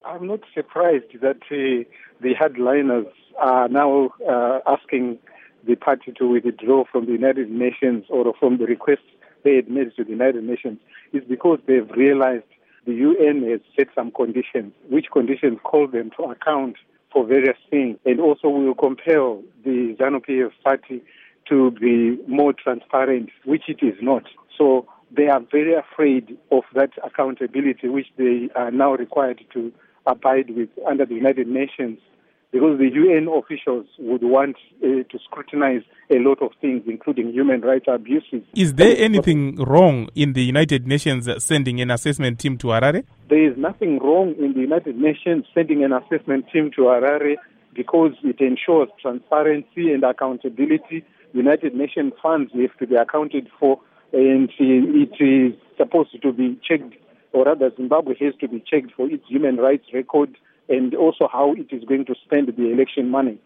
Interview With Tendai Biti